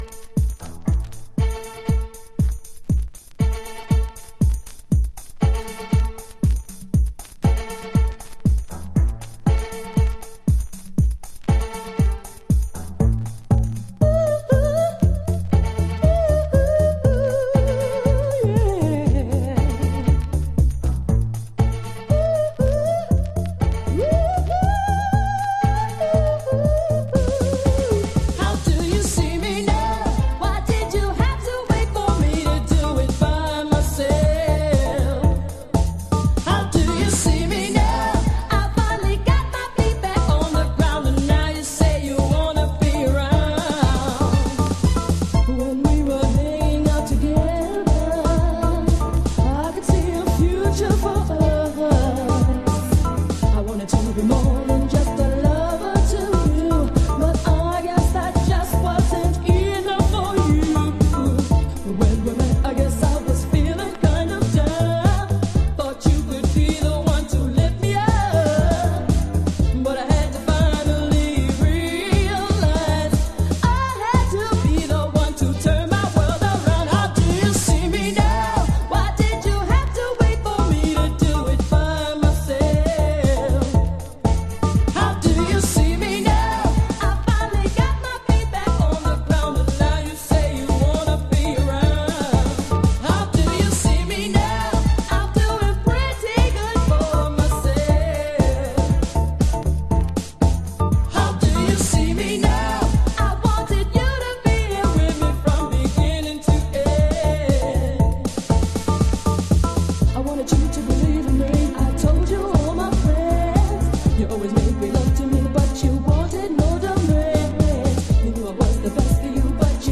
心に滲みるディープハウスオブソウル。